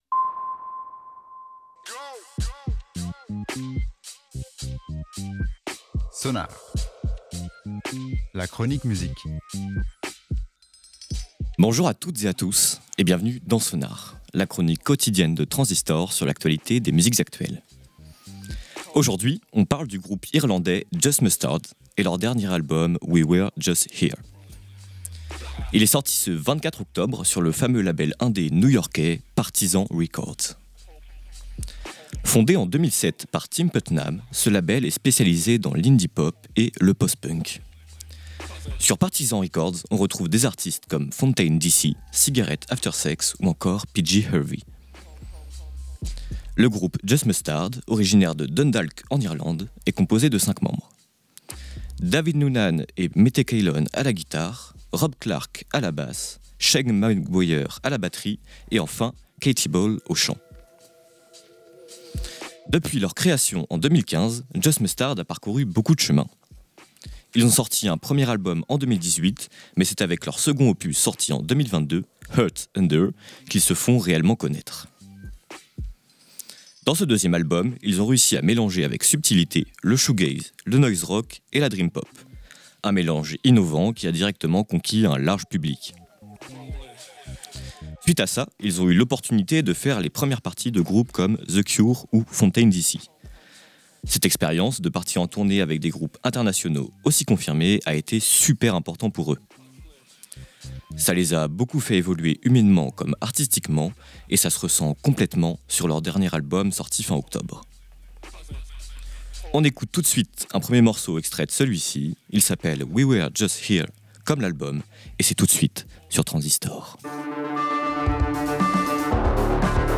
à la guitare
à la basse
à la batterie
au chant